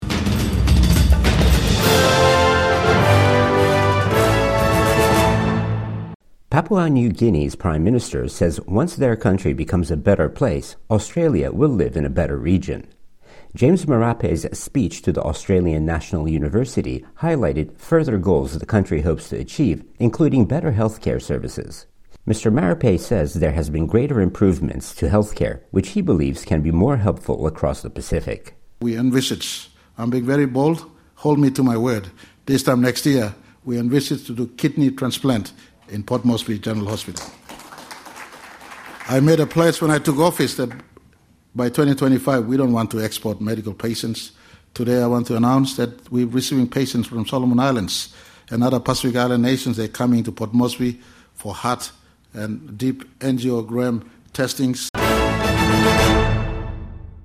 Papua New Guinea's Prime Minister speaks at the Australian National University